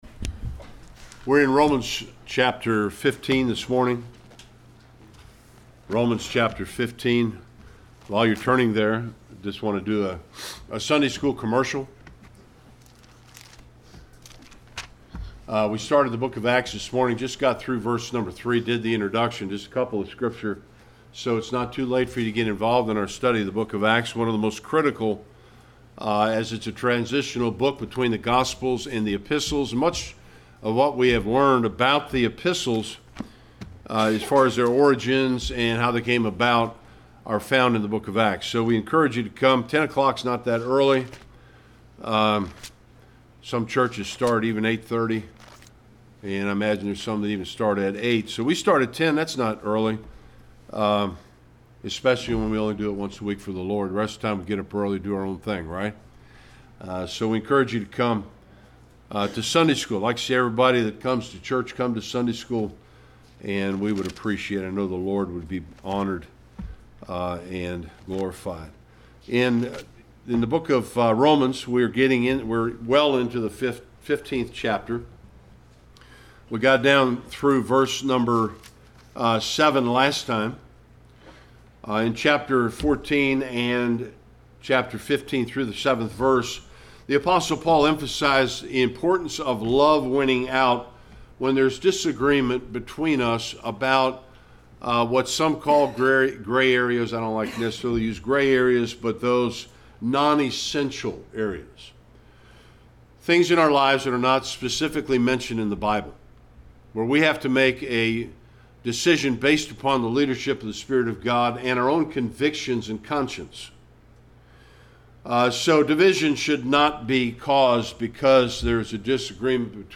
Romans 15:8-21 Service Type: Sunday Worship Bible Text